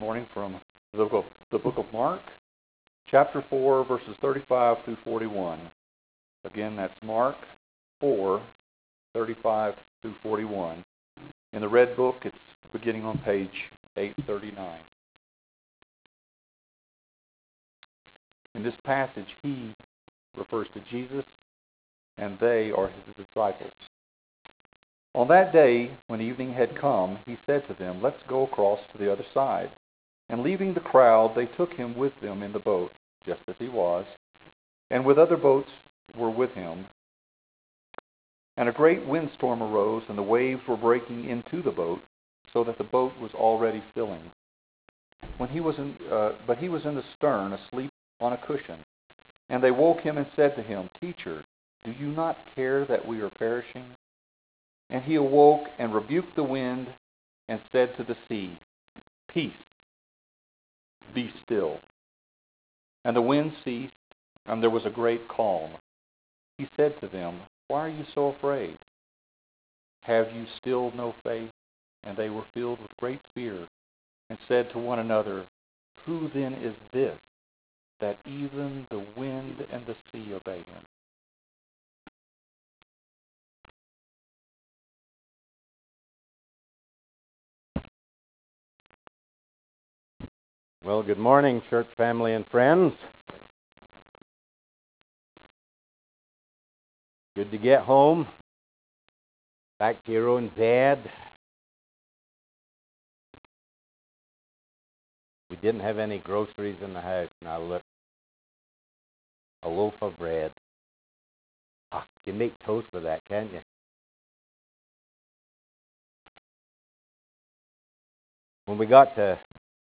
Sept 28 2014 AM sermon